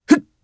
Theo-Vox_Landing_jp_b.wav